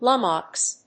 音節lum・mox 発音記号・読み方
/lˈʌməks(米国英語)/